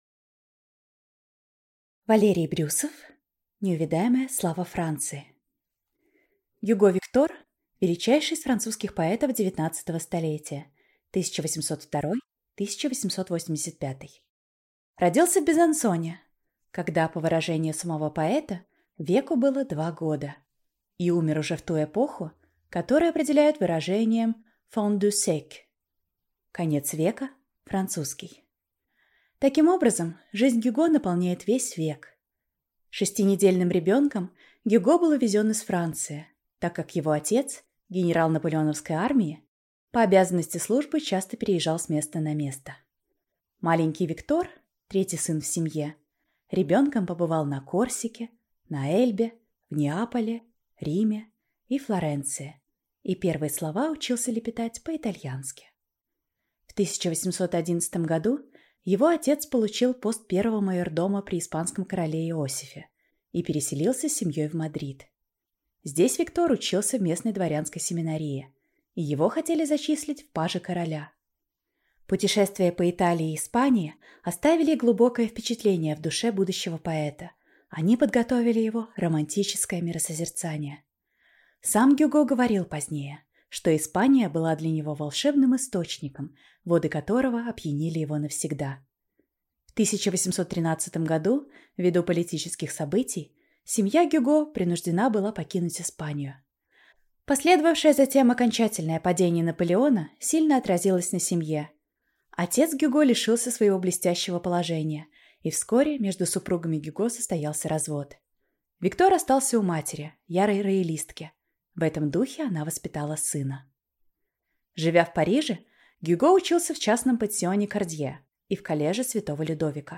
Аудиокнига Неувядаемая слава Франции | Библиотека аудиокниг